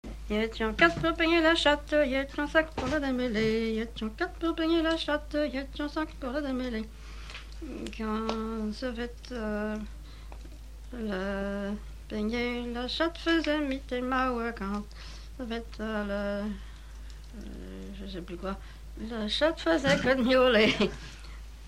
Note maraîchine
Couplets à danser
branle : courante, maraîchine
Catégorie Pièce musicale inédite